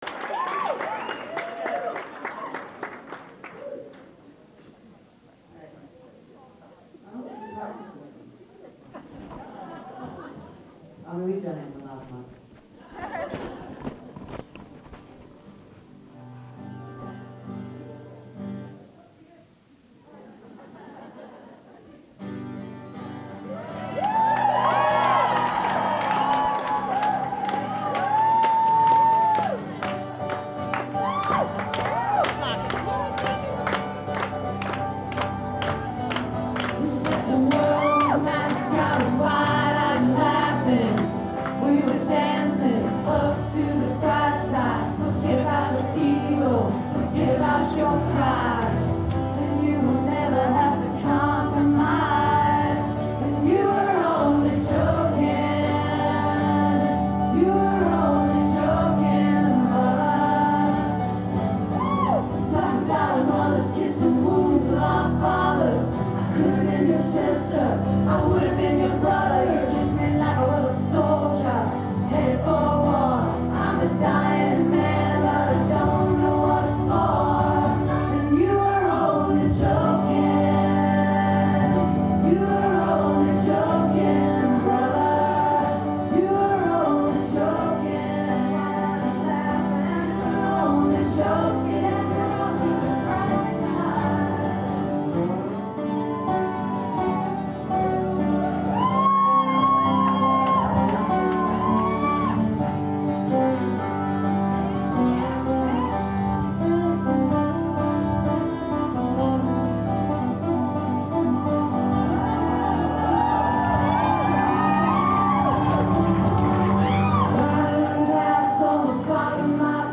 birchmere music hall - alexandria, virginia